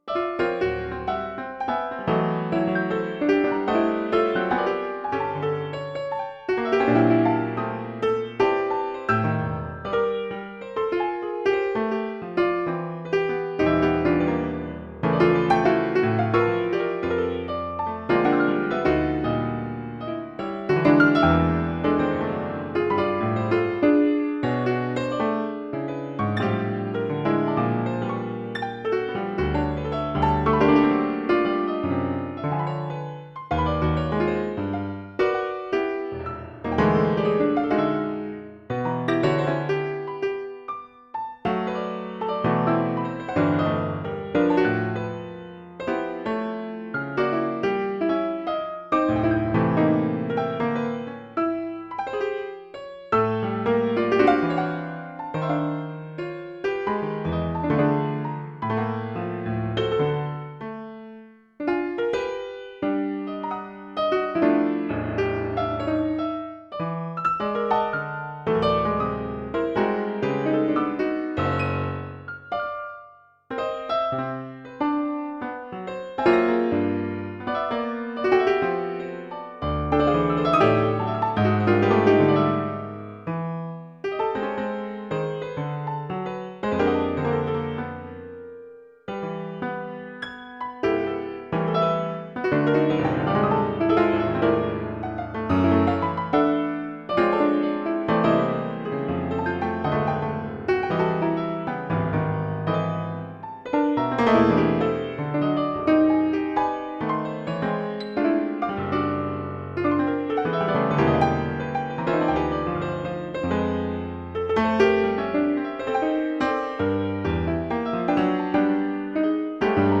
Antisonata exists as an 'infallible' version performed by a MIDI-controlled piano, and a 'fallible' version played a live musician interpreting the notation as best as possible.